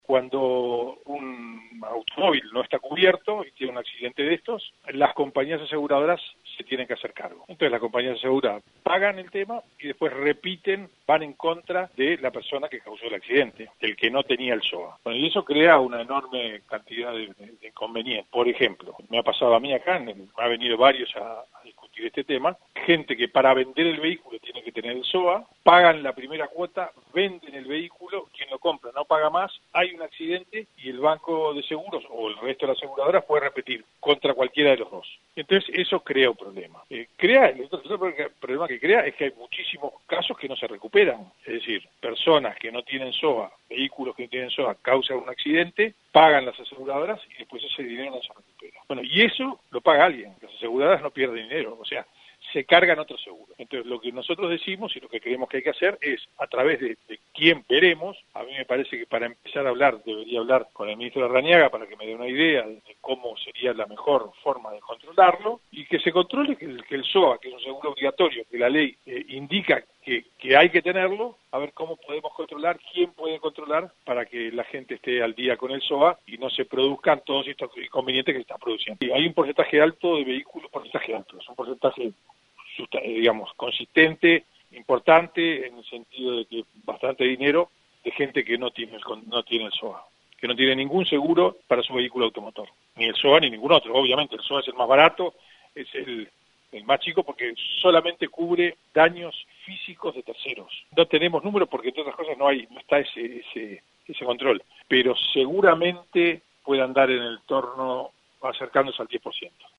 En diálogo con 970 Noticias dijo que el 10% de los vehículos pagan solamente la primera cuota y luego dejan de abonar.